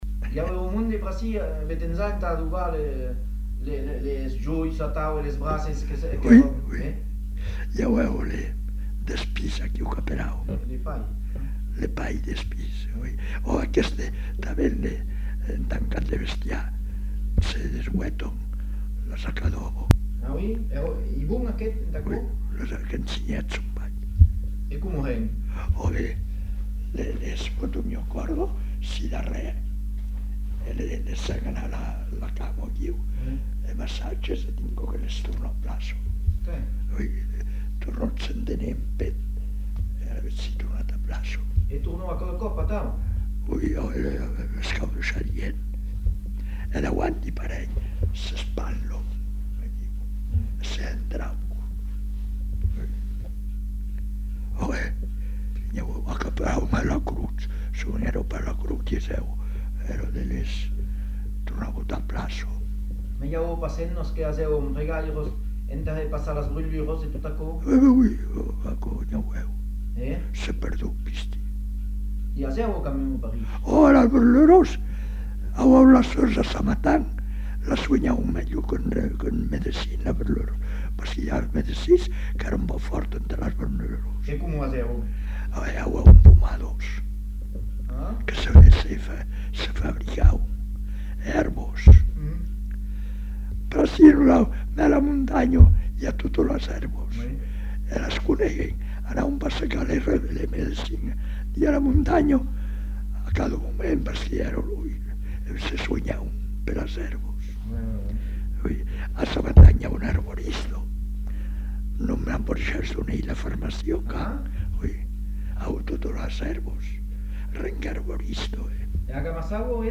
Lieu : Monblanc
Genre : témoignage thématique